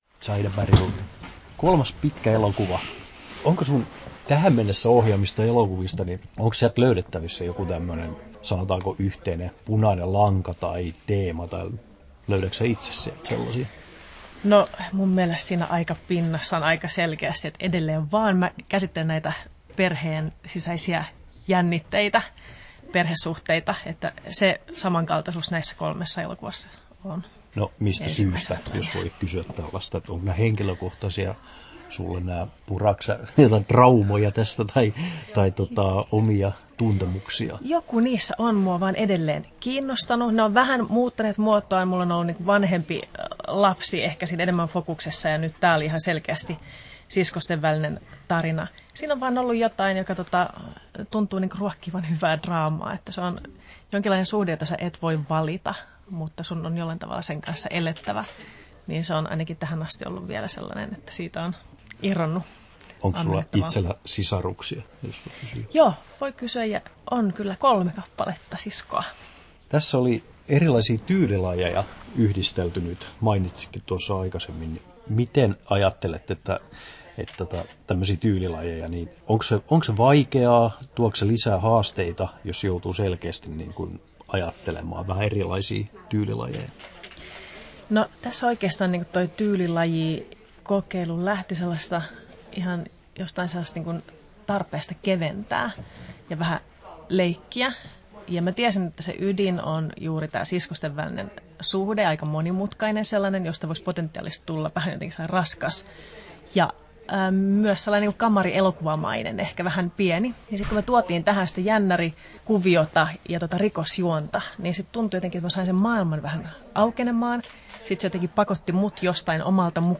Haastattelussa Zaida Bergroth Kesto: 9'33" Tallennettu: 13.06.2017, Turku Toimittaja